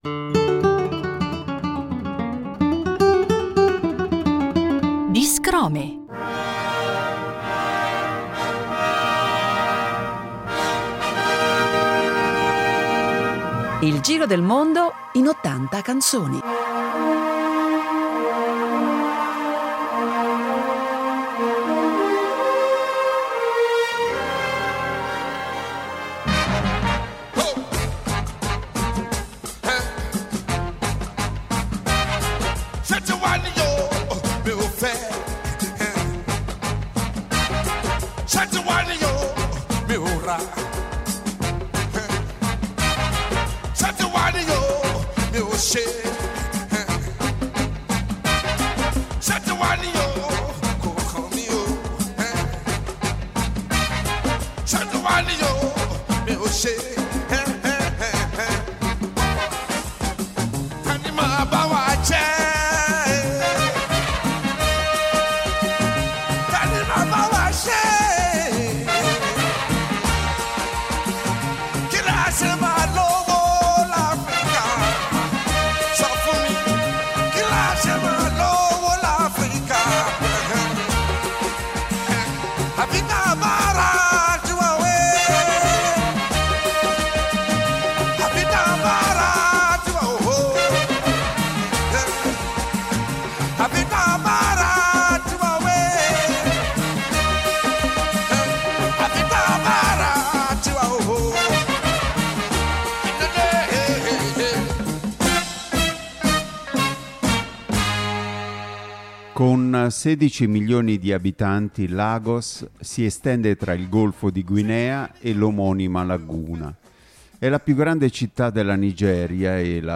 che ci fa viaggiare con la musica attraverso una selva di variegate sonorità